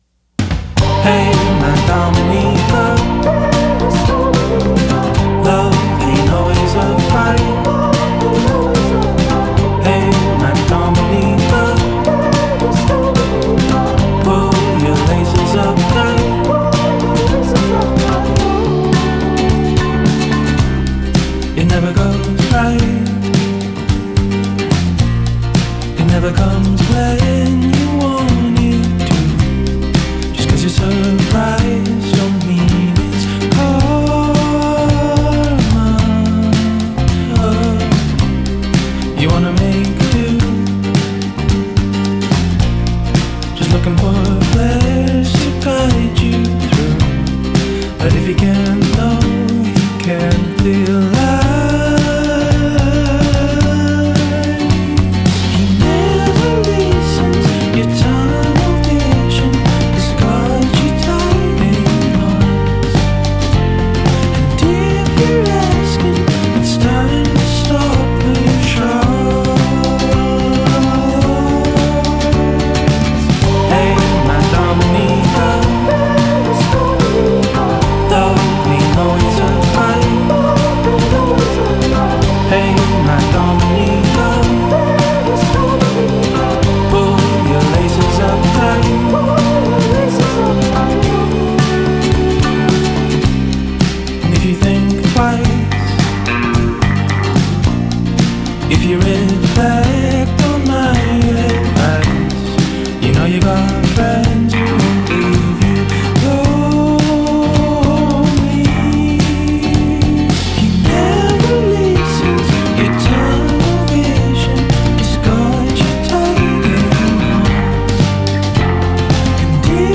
Western Canadian indie music mix